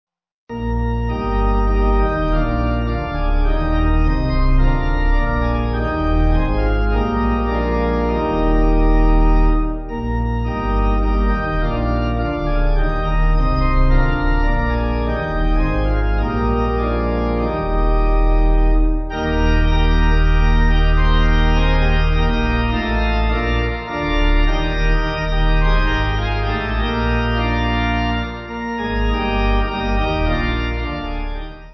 Swiss folk melody
(CM)   3/Eb